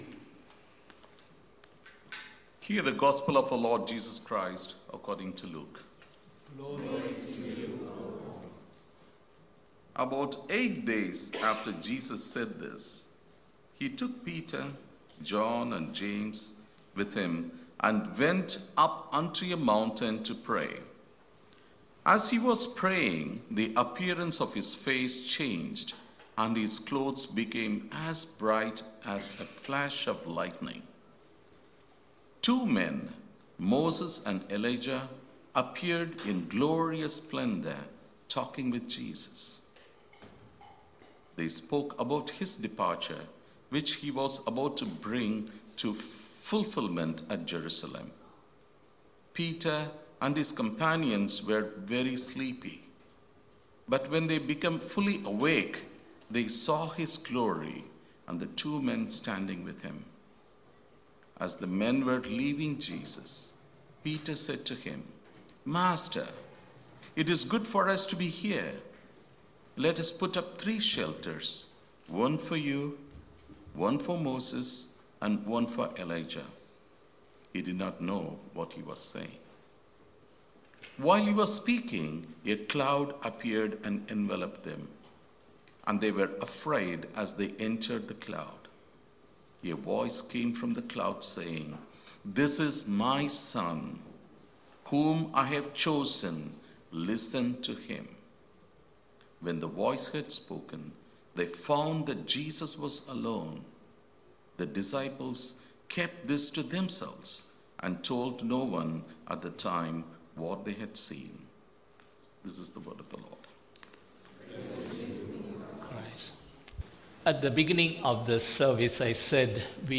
Sermon – March 2